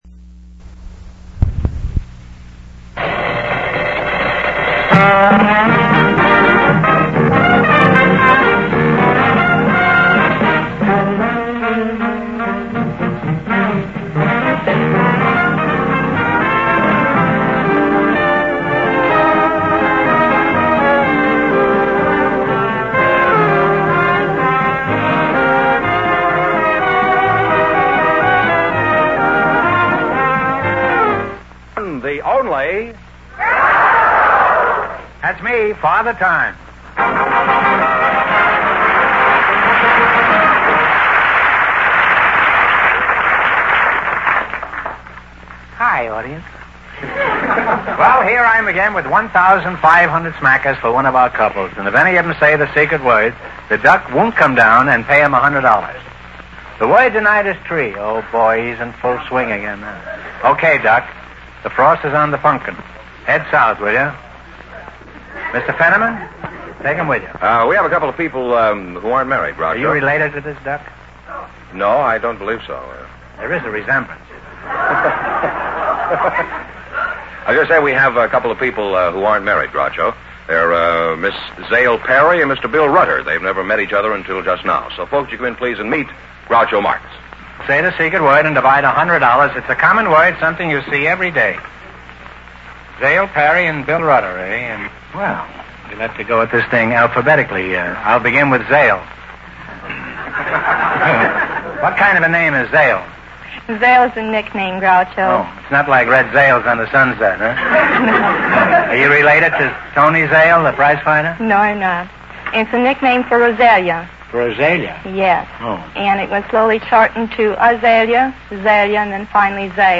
You Bet Your Life Radio Program, Starring Groucho Marx